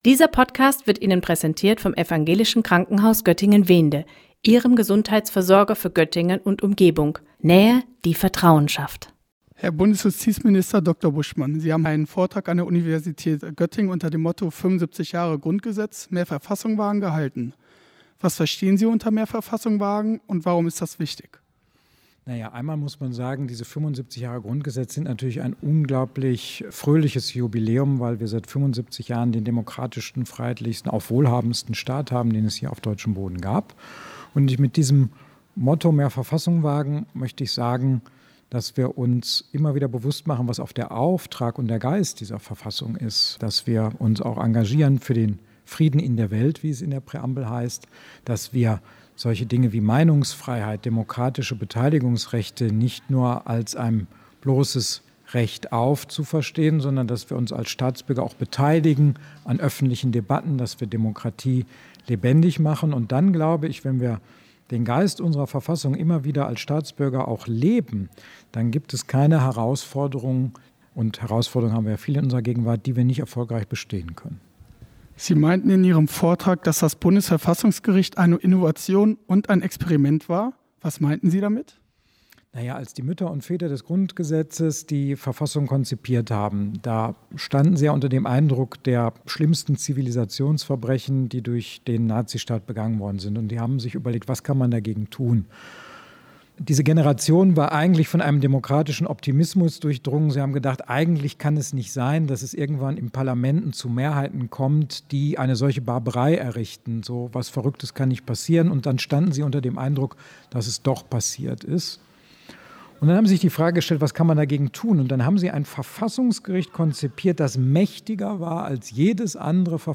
Beiträge > 75 Jahre Grundgesetz: Bundesjustizminister Buschmann im Interview - StadtRadio Göttingen